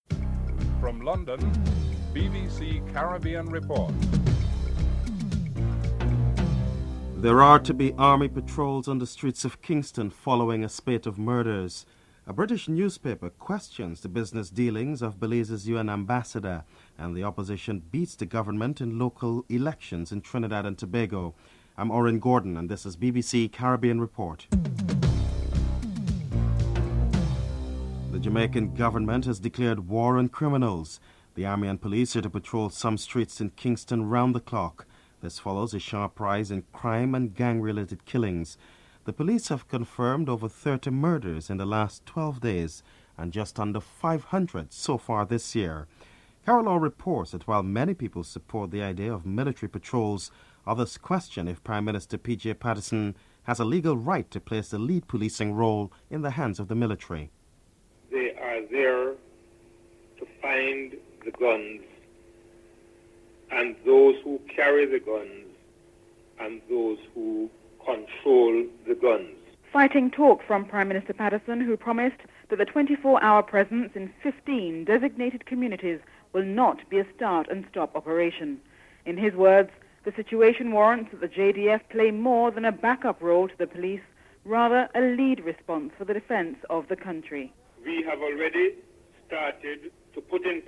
Prime Minister PJ Patterson discusses the need for the joint patrols, curfews and securing assistance from overseas security agencies.
Prime Minister Basdeo Panday gives a postmortem on election results.